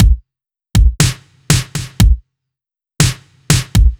Index of /musicradar/french-house-chillout-samples/120bpm/Beats
FHC_BeatA_120-03_KickSnare.wav